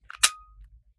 9mm Micro Pistol - Dry Trigger 002.wav